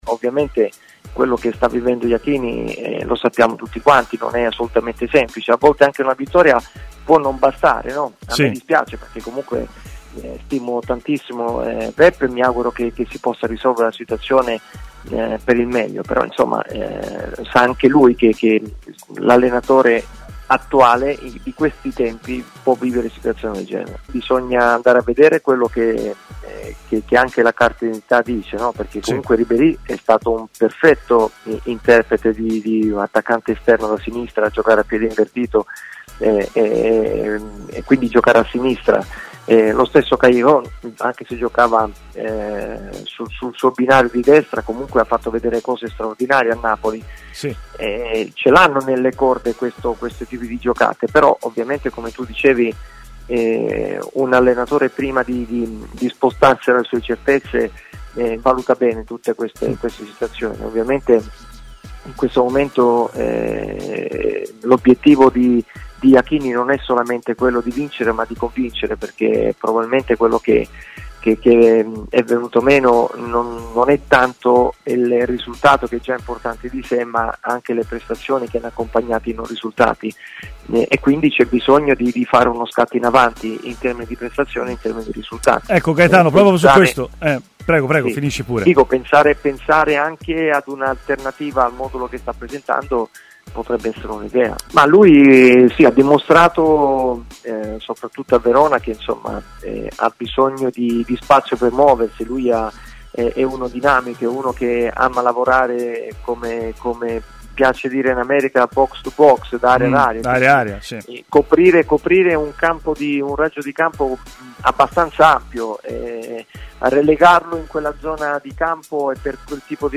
Domanda da un ascoltatore: Castrovilli le somiglia?